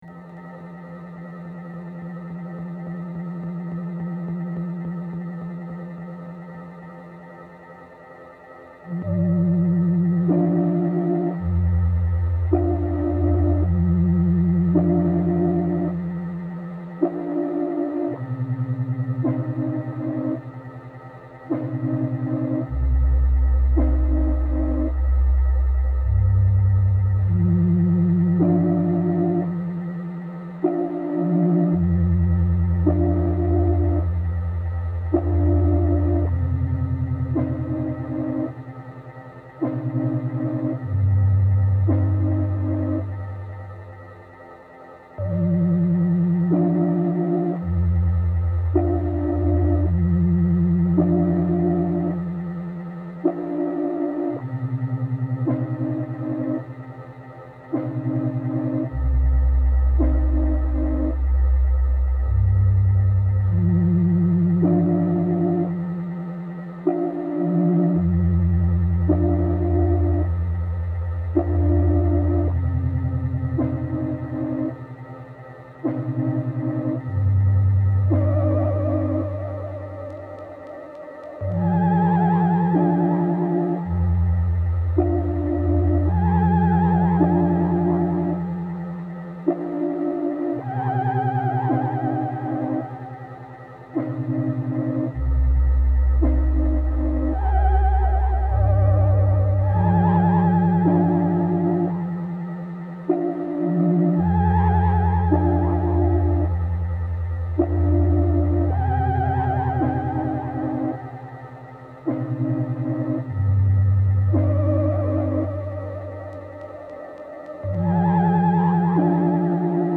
Creepy paranormal activity.